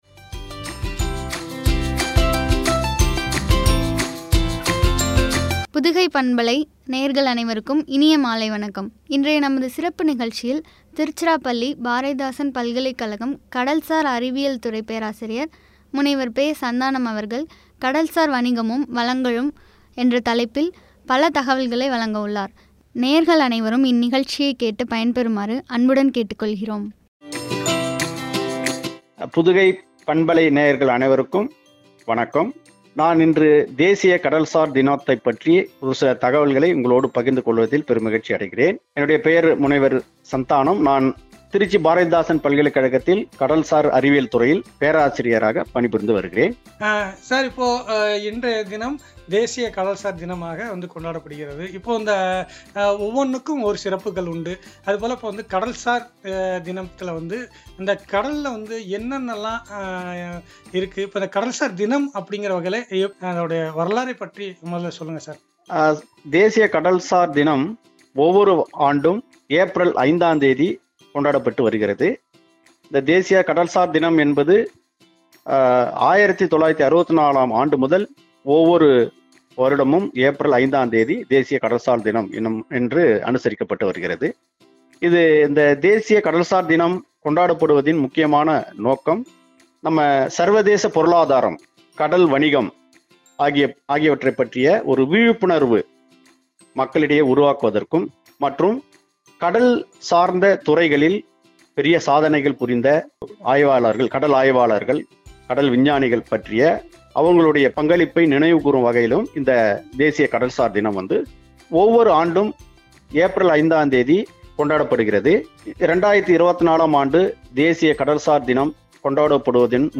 என்ற தலைப்பில் வழங்கிய உரையாடல்.